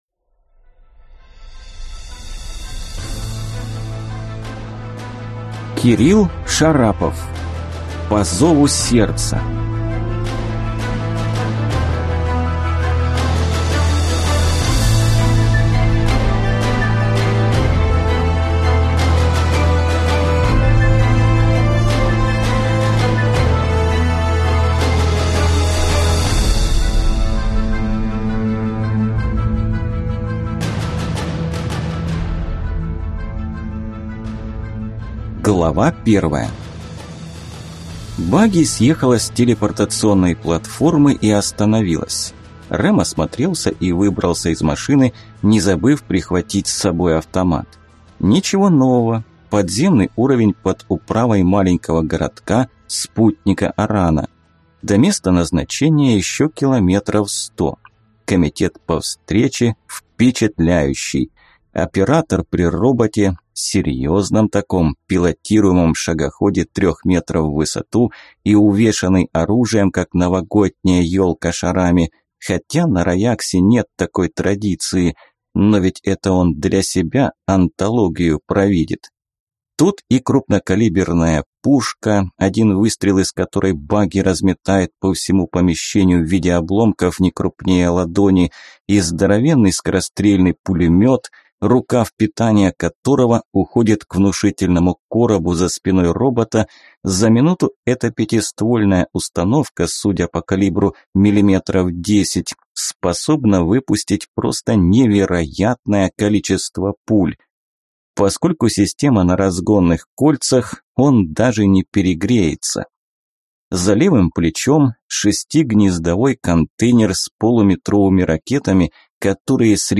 Aудиокнига По зову сердца
Читает аудиокнигу